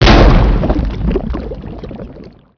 slime.wav